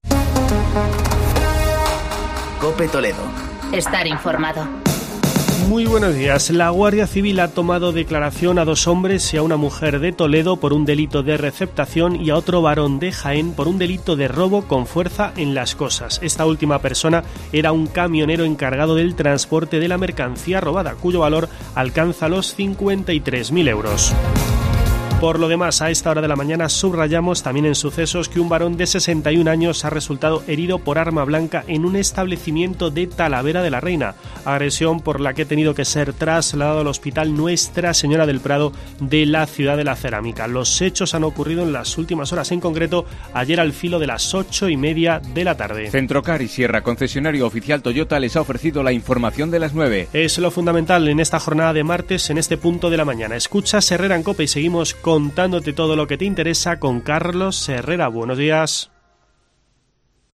Escucha en la parte superior de esta noticia el boletín informativo de COPE Toledo en el que te contamos esta operación de la Guardia Civil.